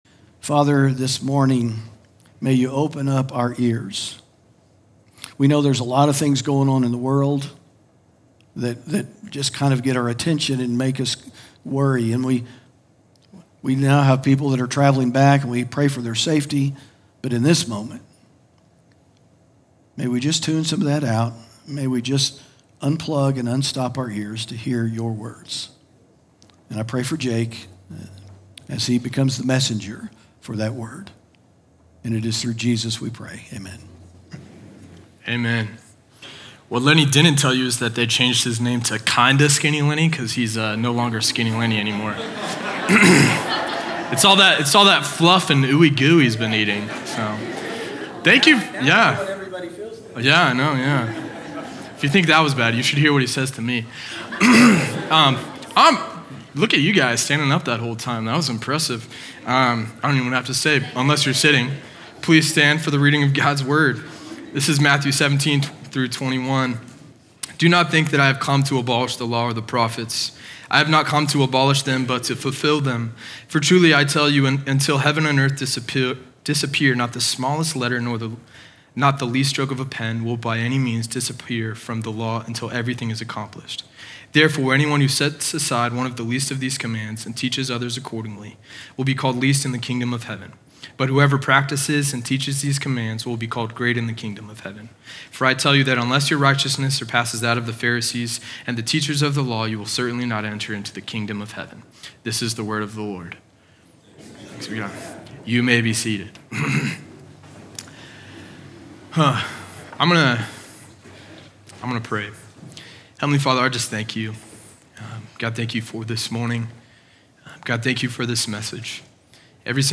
sermon audio 0622.mp3